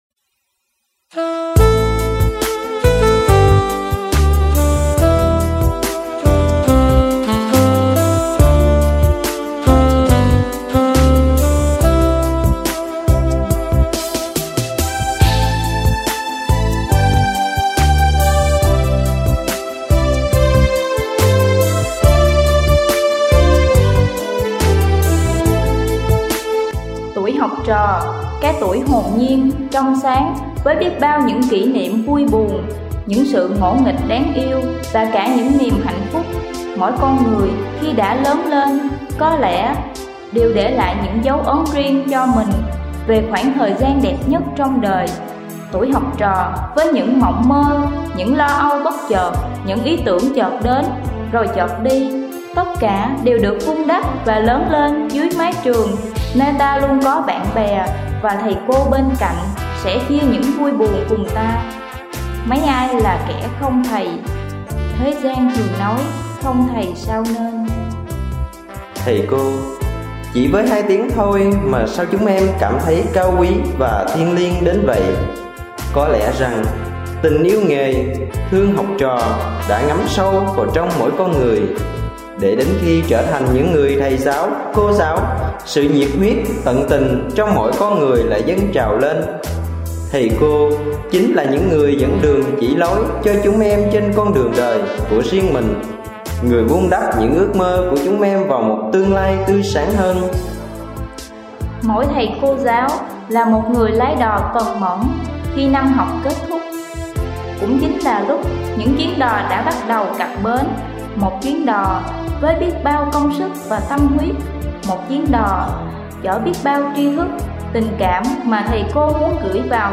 Phát thanh
Phát biểu tri ân của sinh viên nhân ngày 20/11